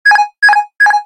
autopilot.ogg